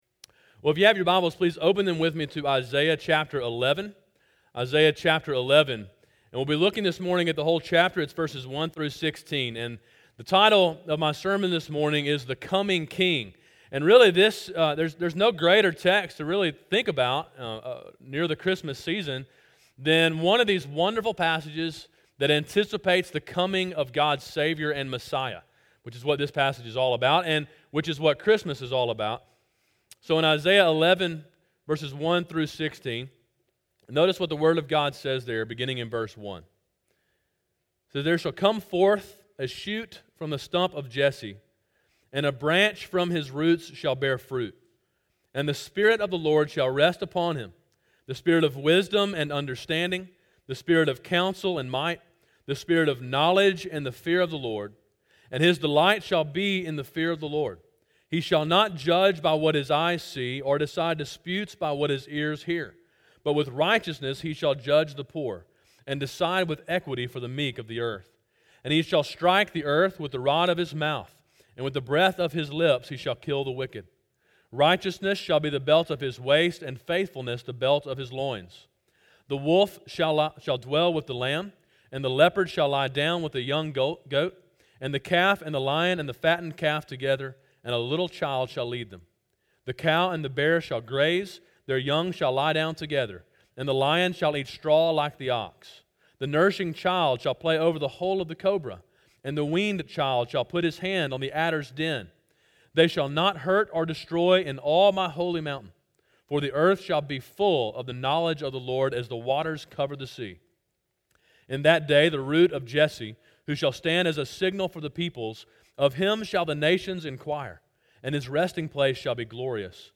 Sermon in a series on the book of Isaiah.